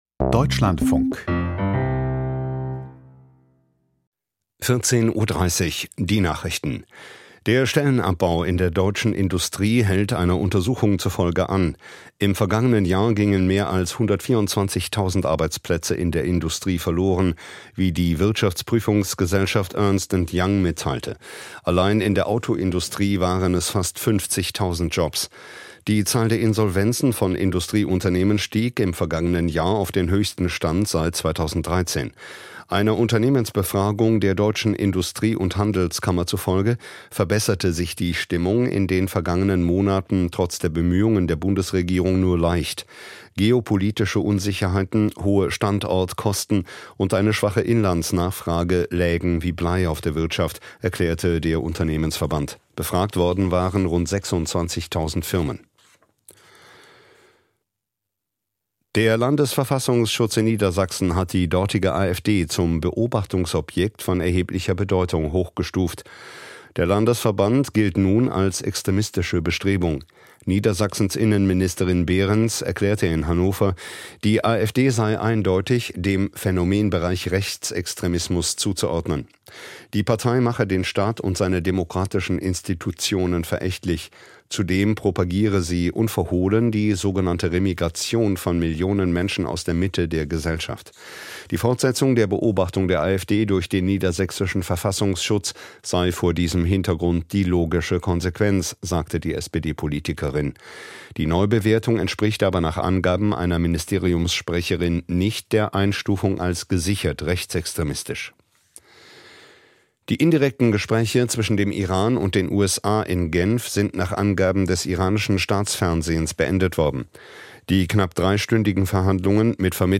Die Nachrichten vom 17.02.2026, 14:30 Uhr
Aus der Deutschlandfunk-Nachrichtenredaktion.